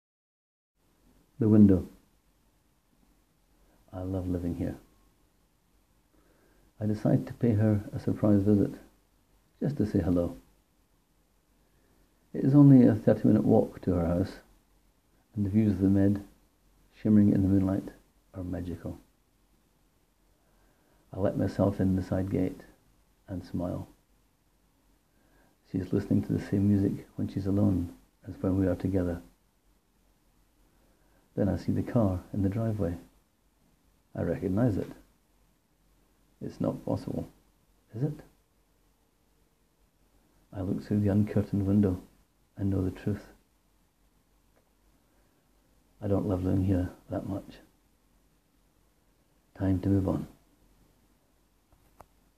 oh dear, I knew when I heard the music it wouldn’t be good.
powerfully moving piece ..and the music was perfect addition 😉